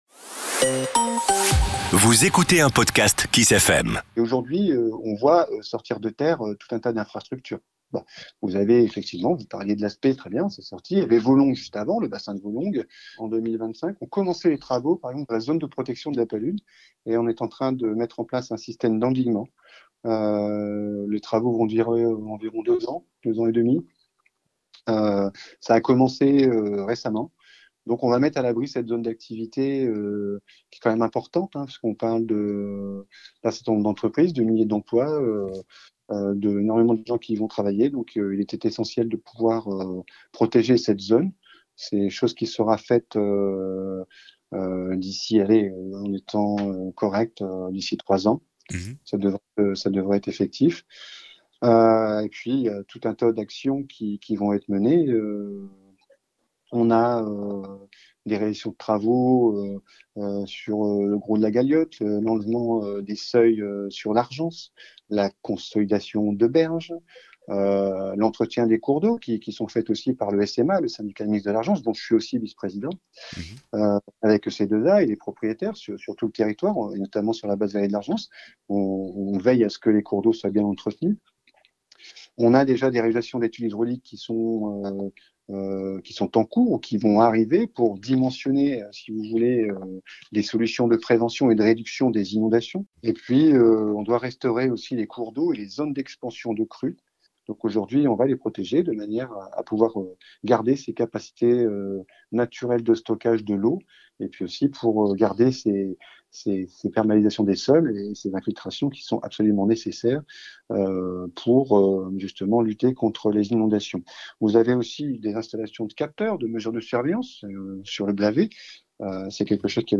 Didier Lemaître, Vice Président d’Estérel Côte d’Azur Agglomération, délégué à la GEMAPI nous raconte :